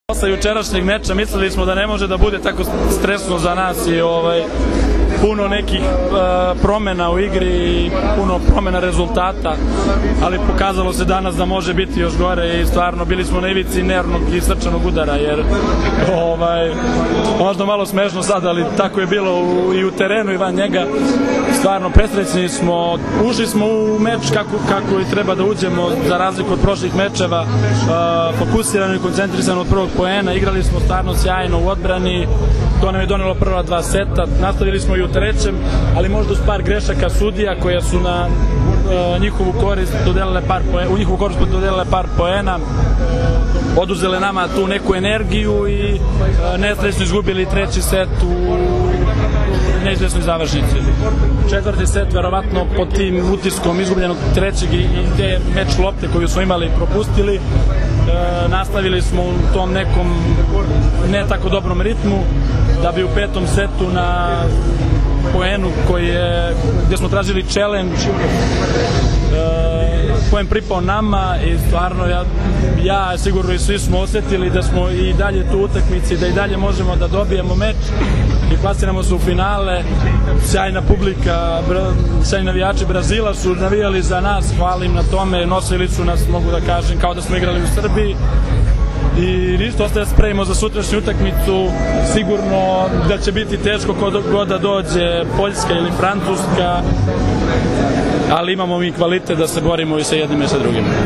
IZJAVA SREĆKA LISINCA